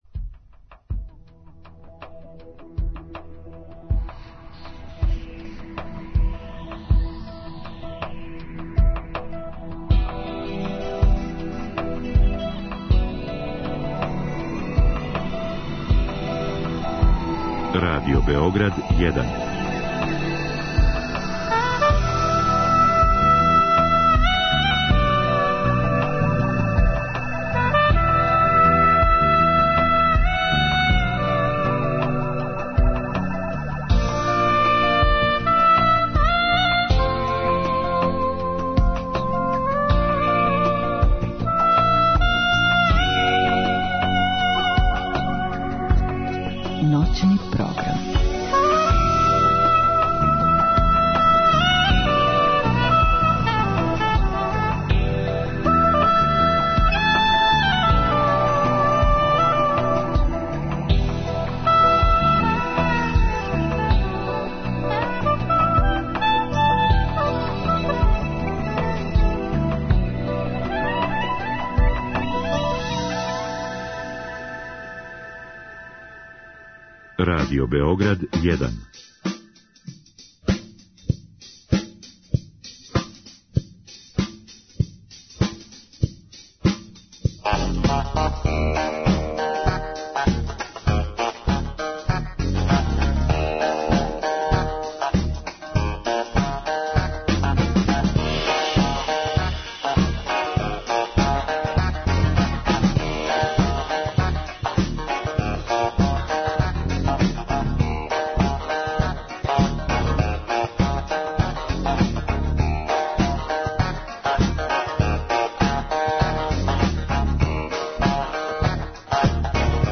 Радио Београд 1, 00.05